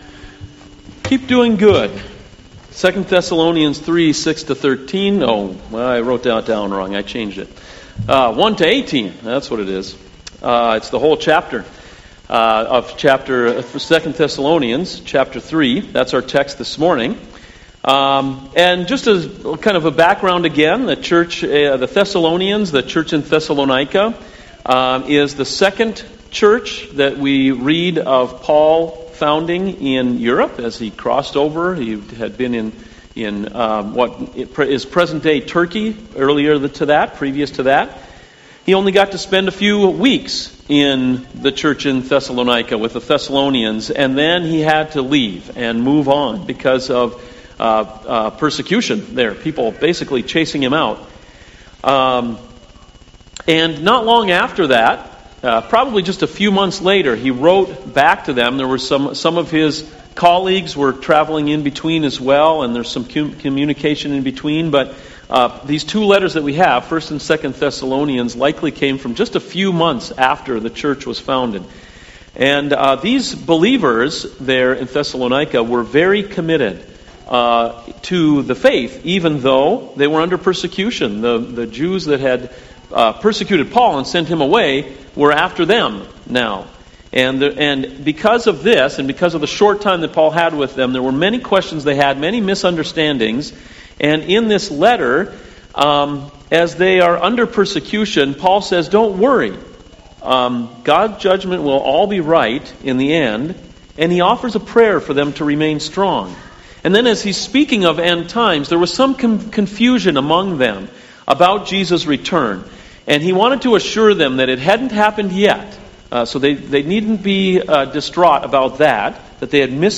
CoJ Sermons Keep Doing Good (2 Thessalonians 3:1-18)